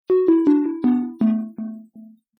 game_over_sfx.mp3